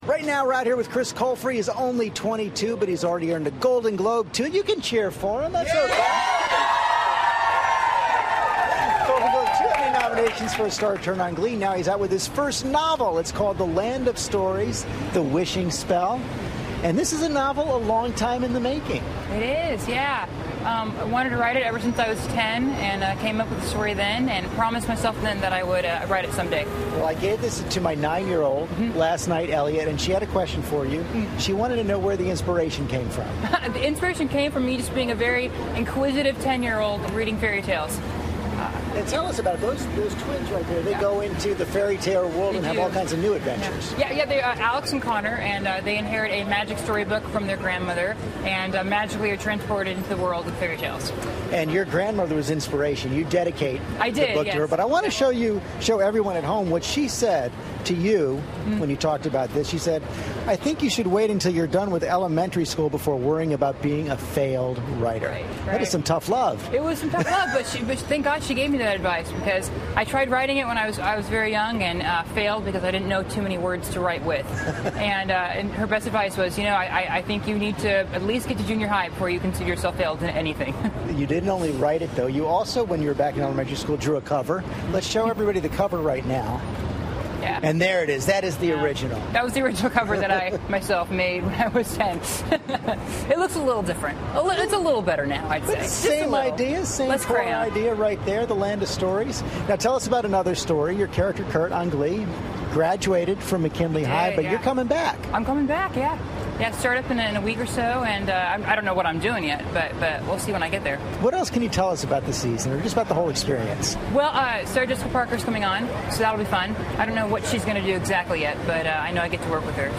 访谈录 2012-07-20&07-22 克里斯.柯尔弗专访 听力文件下载—在线英语听力室